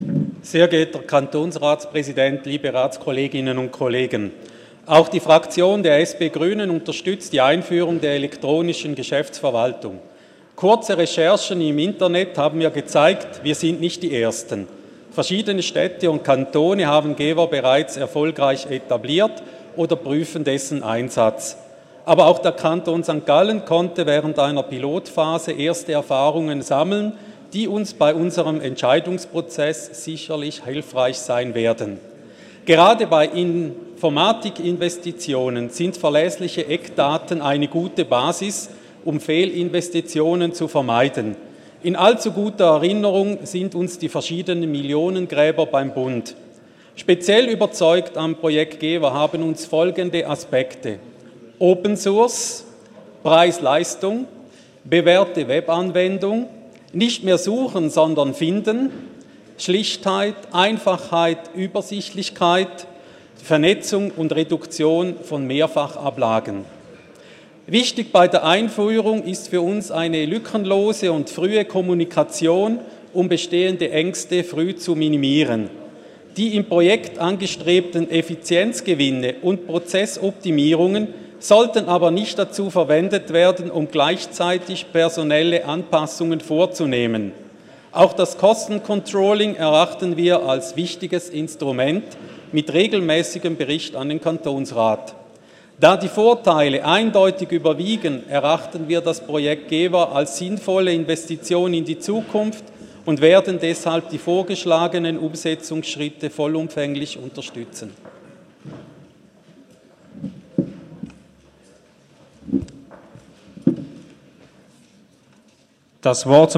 Session des Kantonsrates vom 12. und 13. Juni 2017
(im Namen der SP-GRÜ-Fraktion): Auf die Vorlage ist einzutreten.